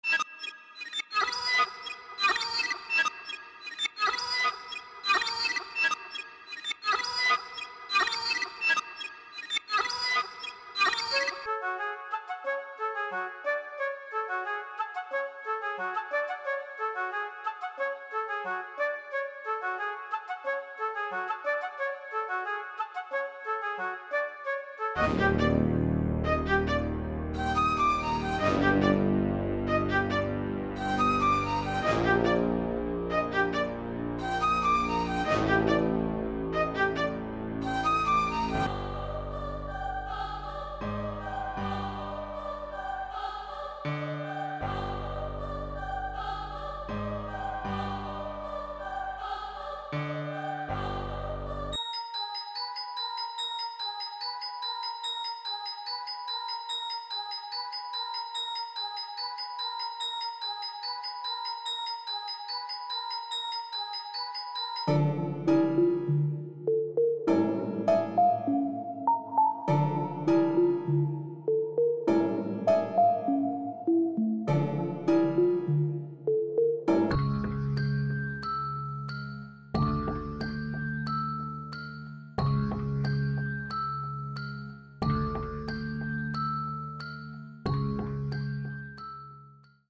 • 20 Melody Loops